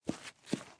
player_walk.ogg